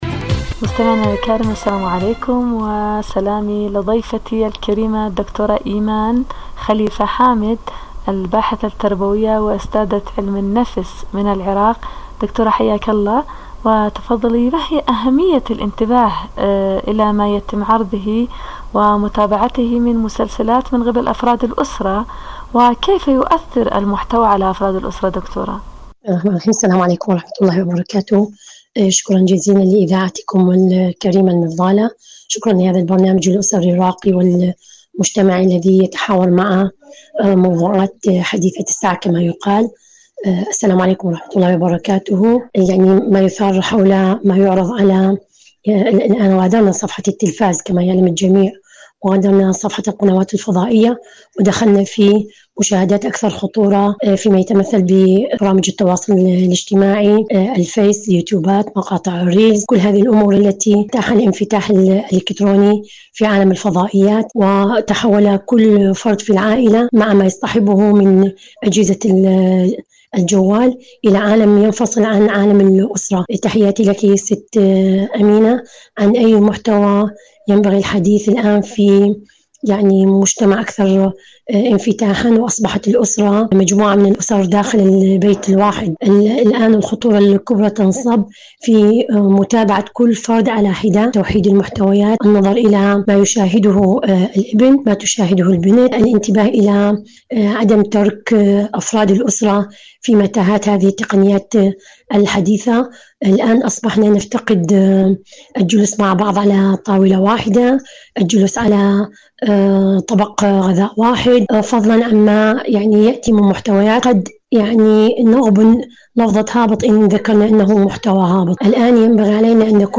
البث المباشر